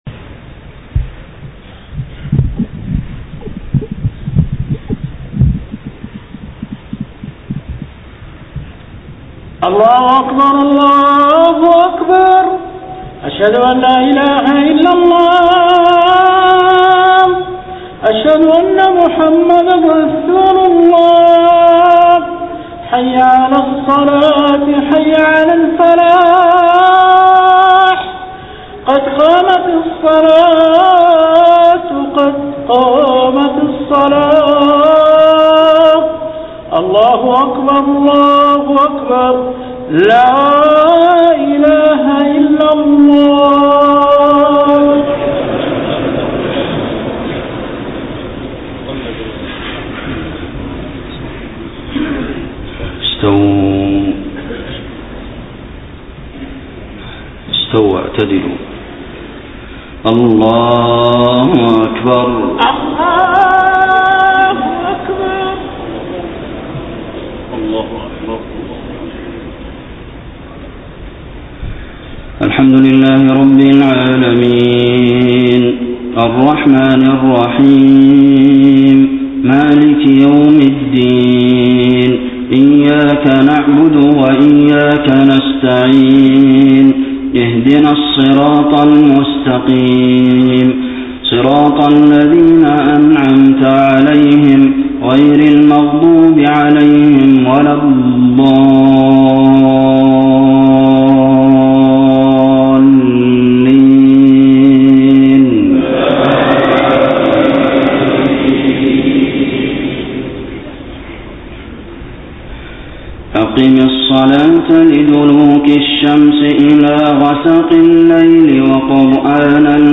صلاة الجمعة 12 ربيع الأول 1431هـ من سورة الإسراء 78-89 > 1431 🕌 > الفروض - تلاوات الحرمين